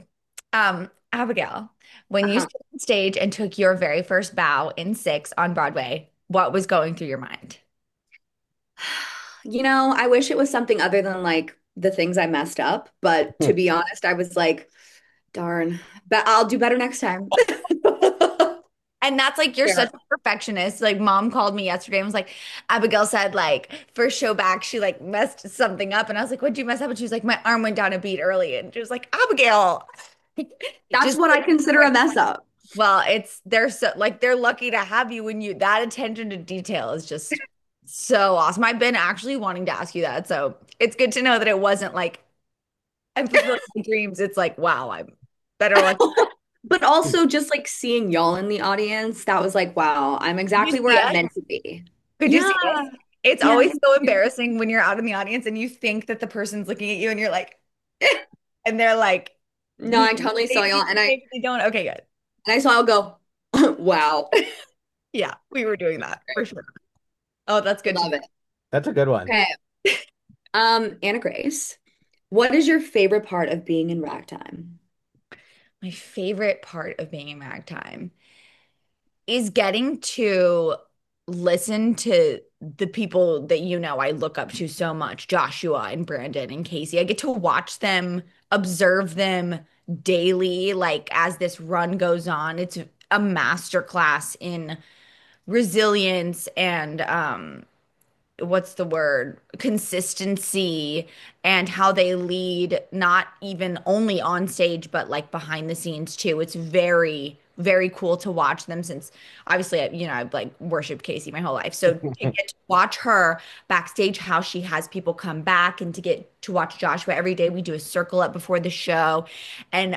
Two Broadway siblings interview each other about this unexpected experience.